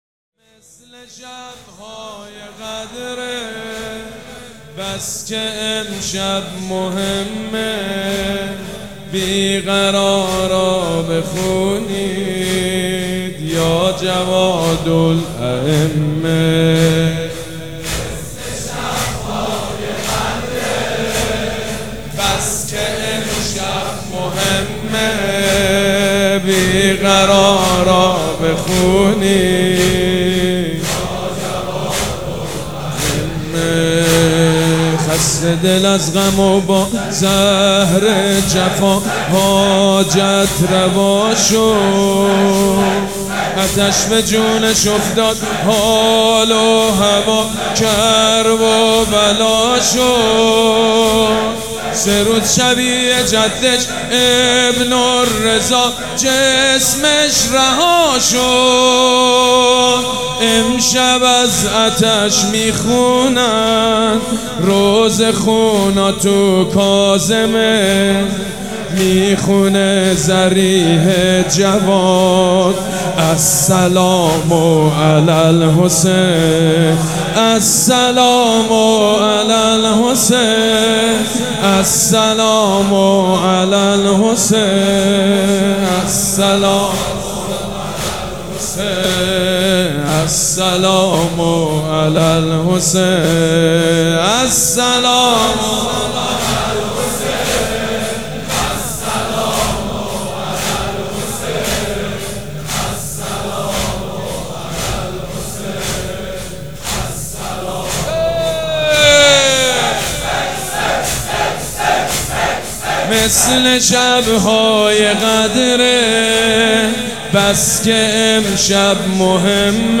مداح
حاج سید مجید بنی فاطمه
شهادت امام جواد (ع)